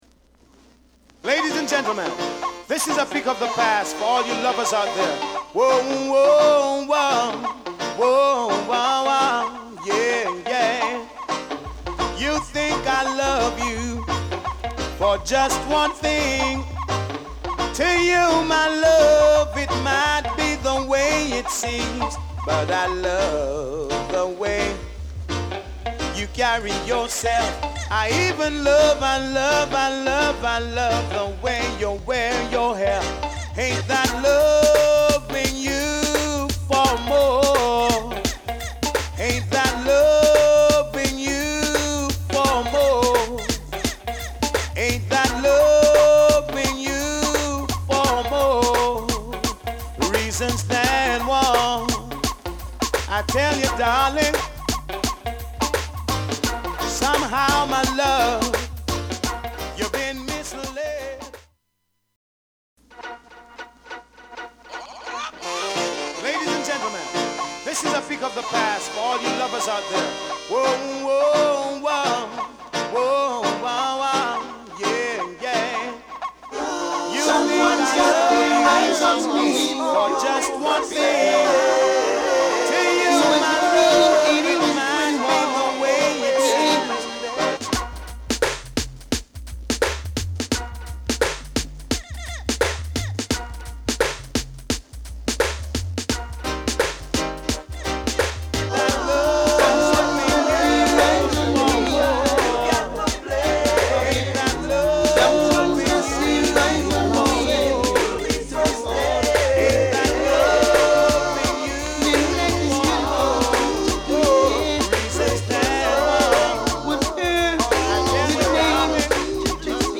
REGGAE / DANCEHALL
盤は擦れや音に影響がある傷がわずかですが有り使用感が感じられます。